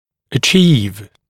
[ə’ʧiːv][э’чи:в]добиваться, достигать